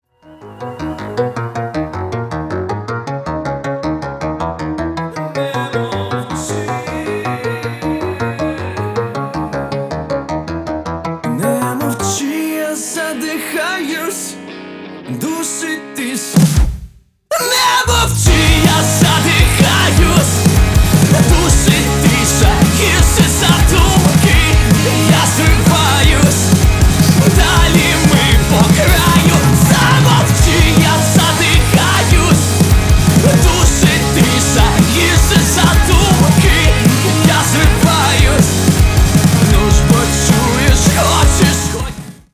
Драйвовые
мелодичные
нарастающие
дуэт
эмоциональные
украинский рок